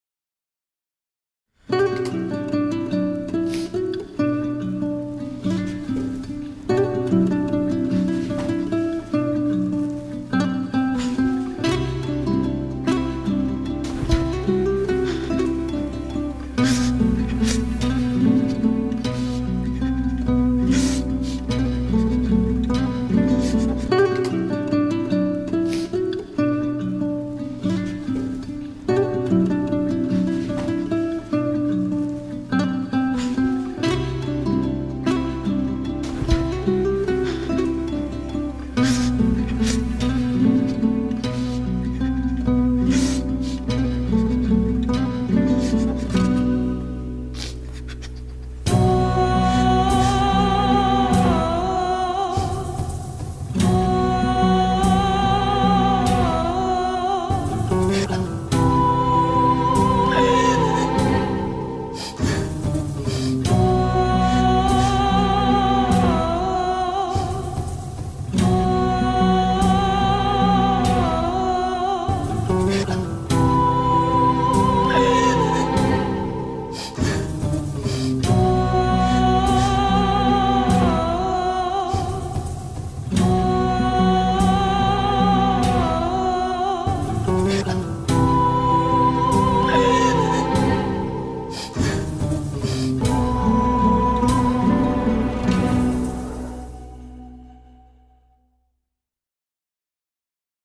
tema dizi müziği, duygusal hüzünlü üzgün fon müziği.